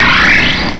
cry_not_klang.aif